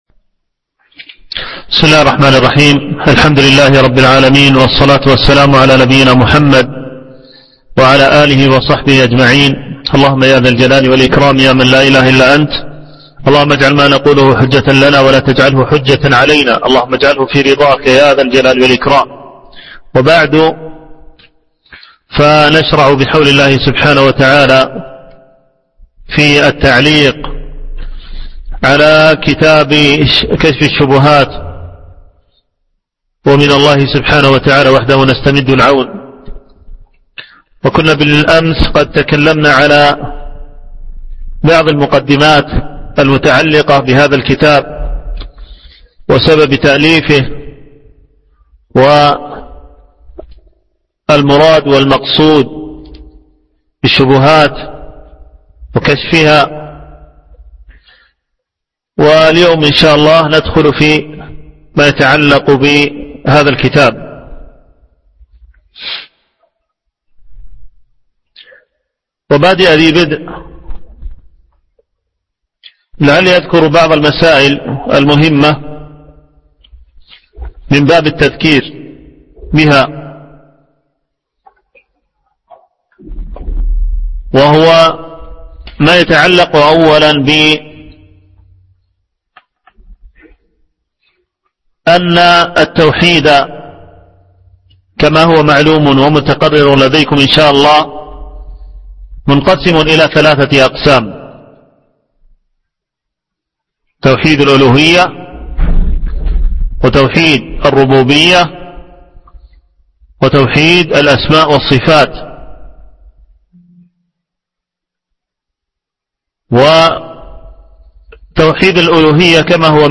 MP3 Mono 22kHz 24Kbps (CBR)